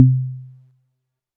Drums_K4(50).wav